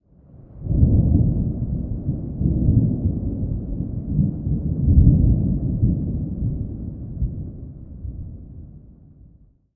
thunder_1.ogg